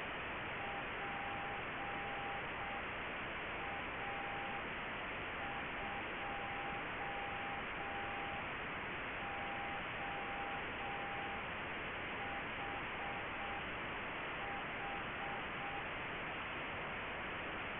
He made already a program in Python that does create a WAV file of a Morsecode signal and adds noise.
Morsecode test signals!
The speed is 6 words per minute (dot time 0.2 sec.).
Audio file -5 dB SNR CASUAL CW, 6 words per minute